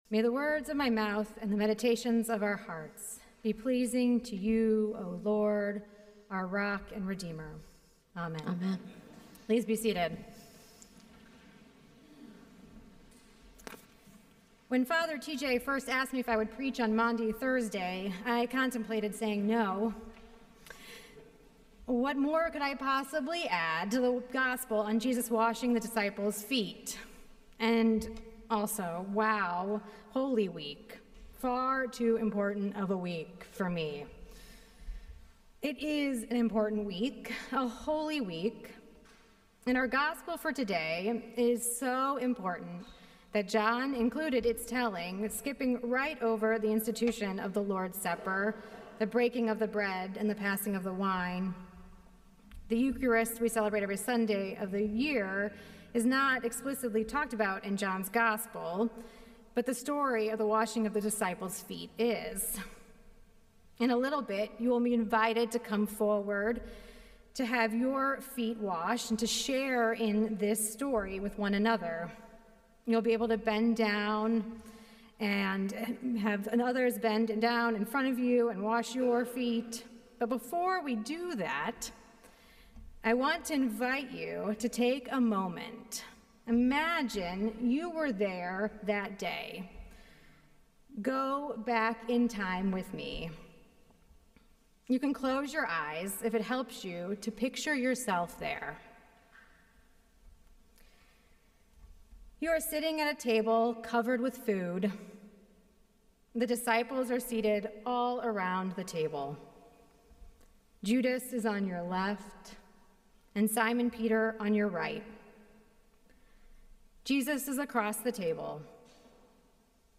Sermons
Sermons from St. Paul’s Episcopal Church, Cleveland Heights, Ohio